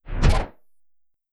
Shooting.wav